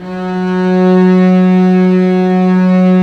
Index of /90_sSampleCDs/Roland LCDP13 String Sections/STR_Vcs FX/STR_Vcs Sordino